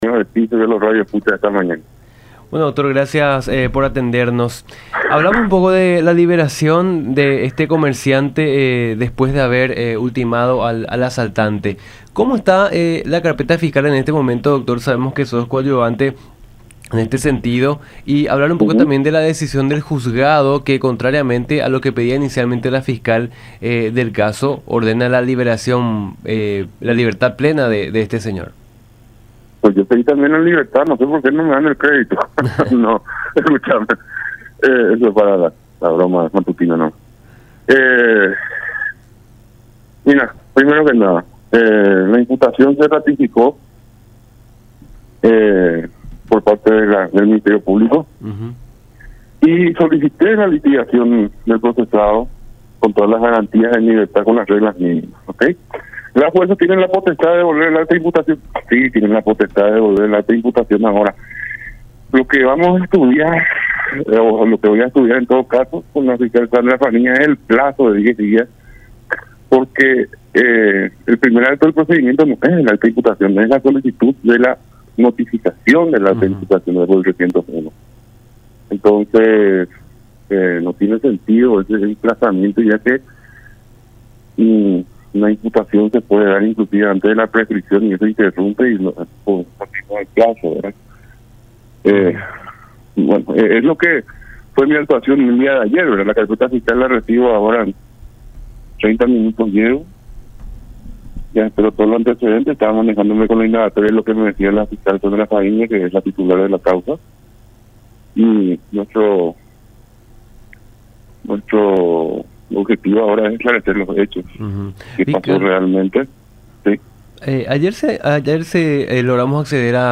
“Nuestro objetivo ahora es poder aclarar el hecho, de cómo realmente se dio la situación. La imputación es cuando hay sospecha de un hecho”, dijo Rienzi también en conversación con Nuestra Mañana por La Unión.